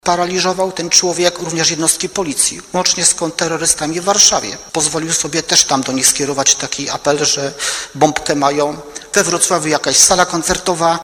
– Jakież było jego zdziwienie, kiedy udało nam się go zlokalizować […]. Jak sprawa tutaj w Bielsku wybrzmiała, uzyskiwaliśmy pomoc Centralnego Biura Zwalczania Cyberprzestępczości – nie byli w stanie go namierzyć – mówił insp. Krzysztof Herzyk, szef Komendy Miejskiej Policji w Bielsku-Białej, który o sukcesie bielskich policjantów poinformował w trakcie ostatniej sesji miejskiej i raportów służb.